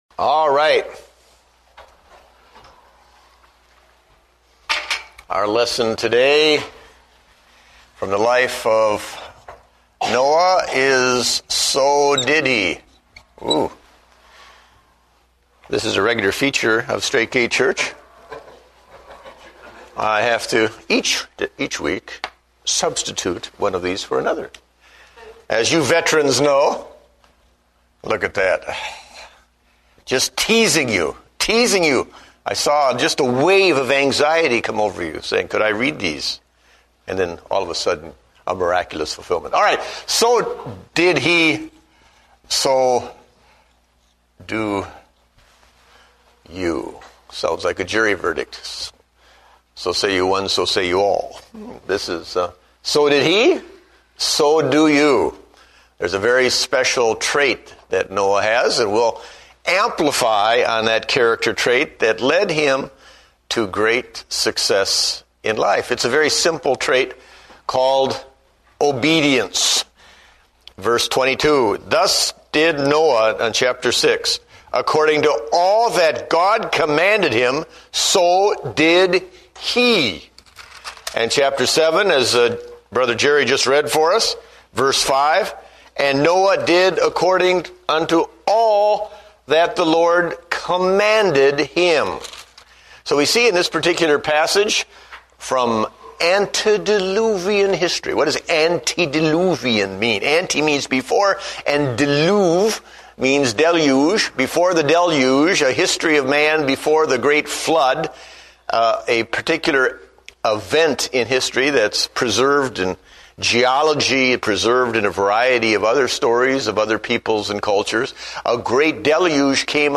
Date: January 25, 2009 (Adult Sunday School)